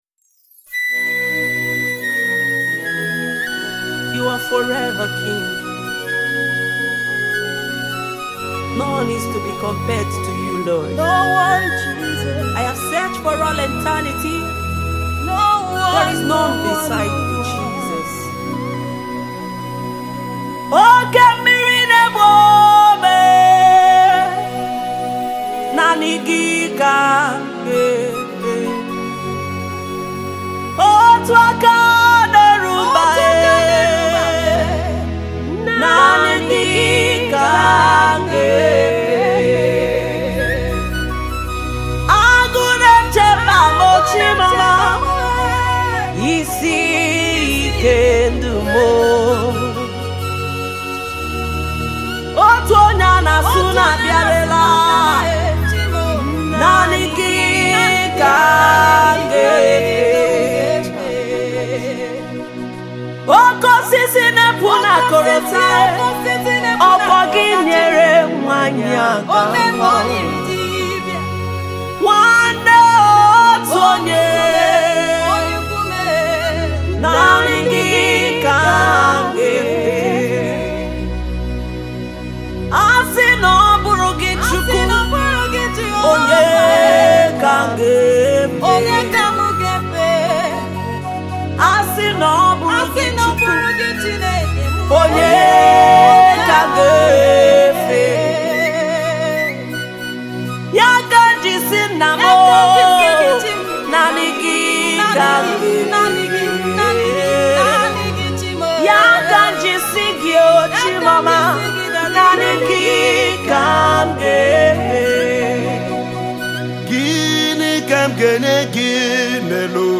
a deep call to worship